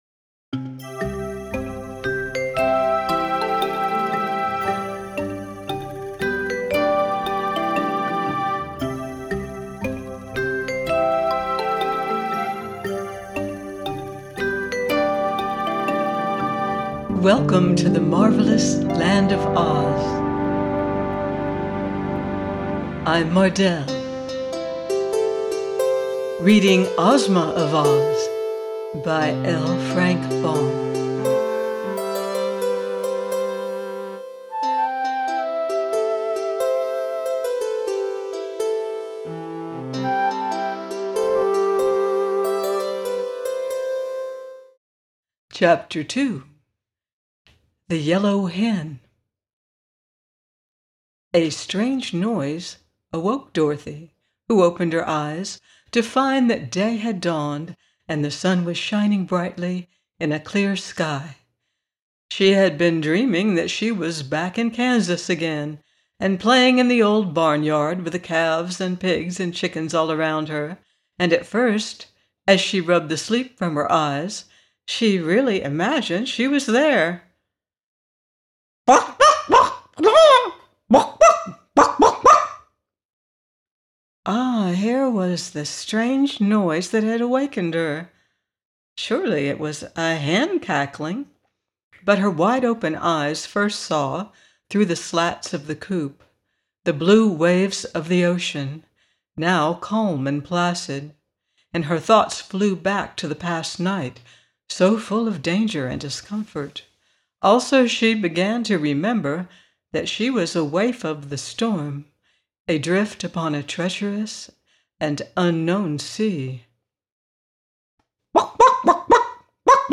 Ozma Of Oz – by L. Frank Baum - audiobook